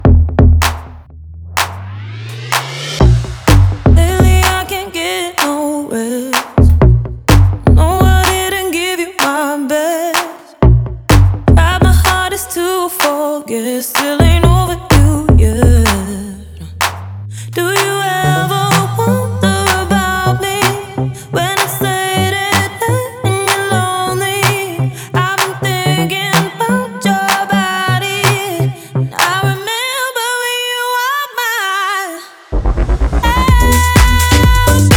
Жанр: Танцевальная музыка
# Dance